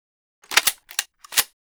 rifle_reload.wav